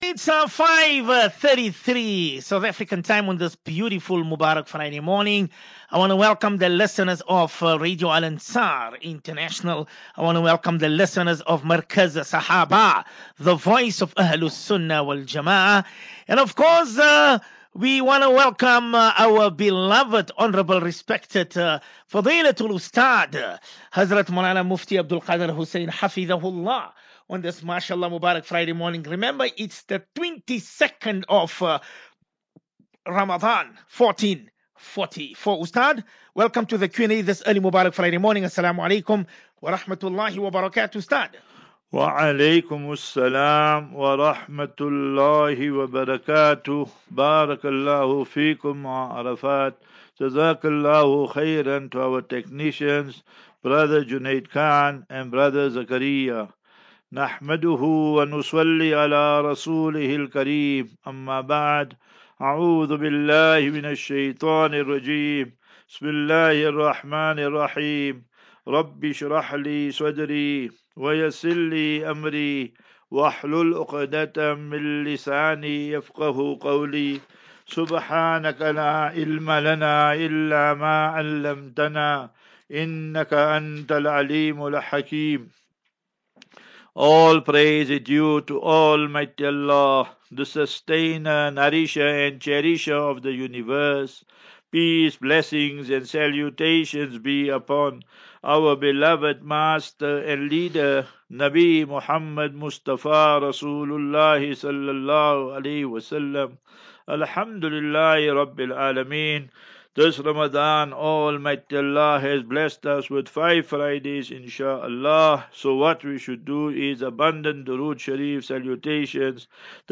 Daily Naseeha.